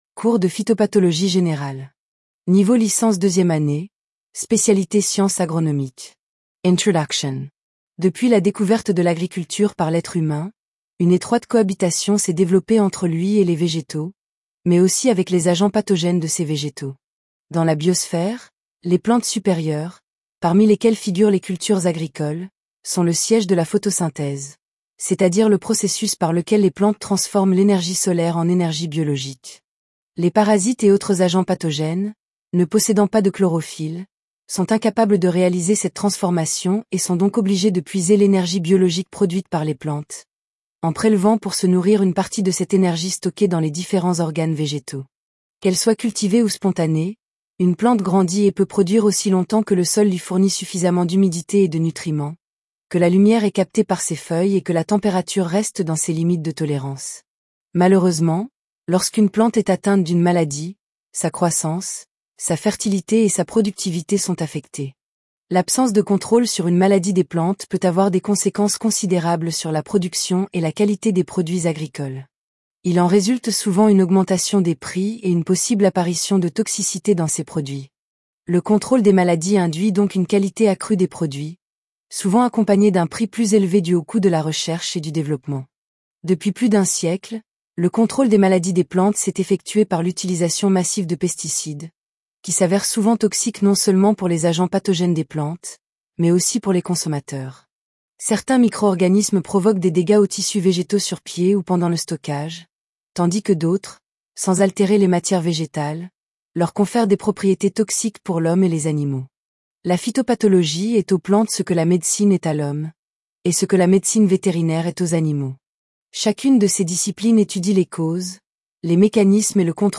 PHYTOPATHOLOGIE GENERALE. L2/SA - Lecture Audio (MP3): PHYTOPATHOLOGIE GENERALE.
Cours_phytopath_chap1_TTS.mp3